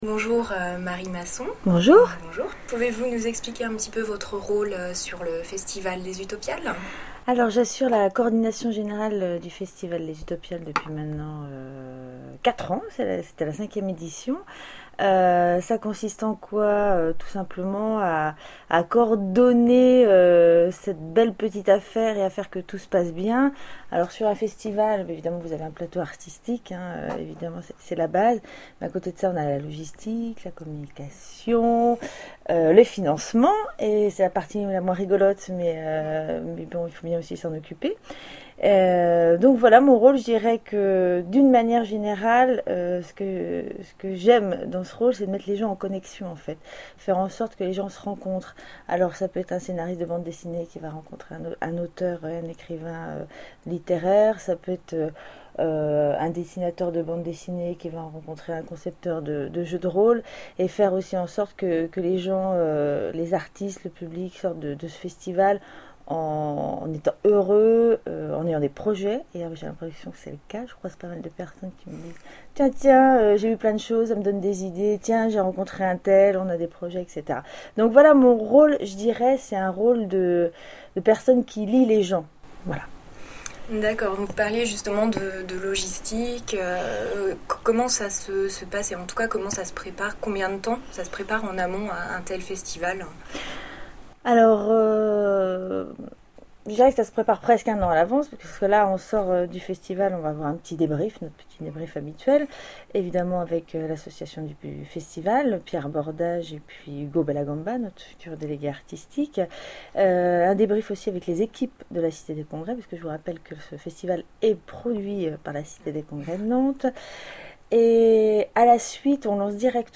Utopiales 2011 : Interview